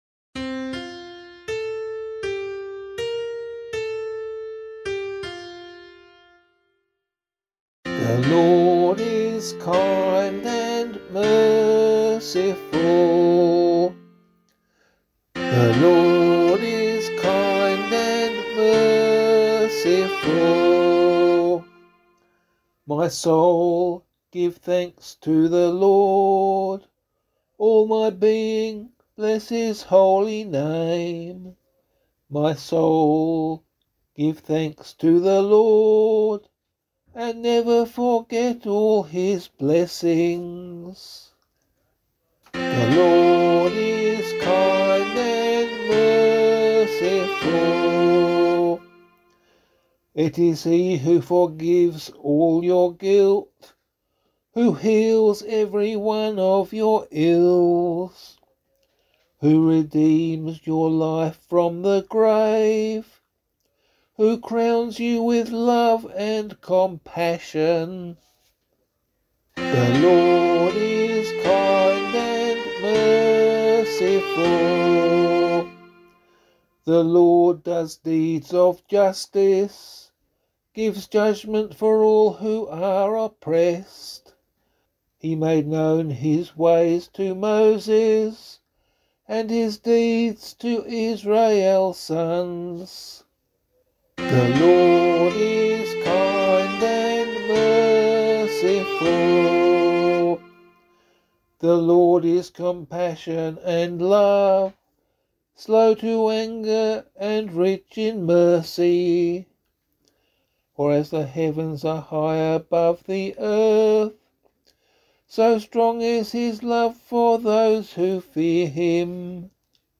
015 Lent 3 Psalm C [LiturgyShare 6 - Oz] - vocal.mp3